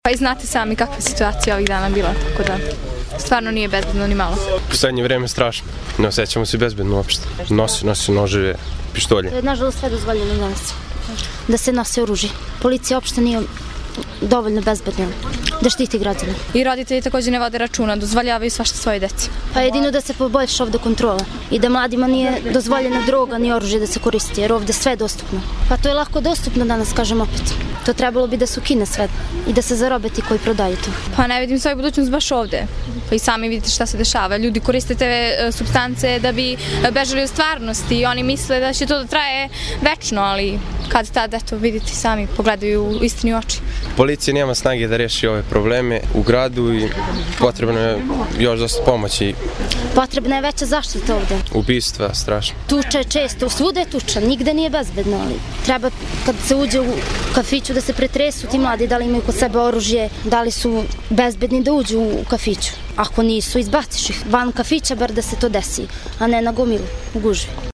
Mladi o bezbednosti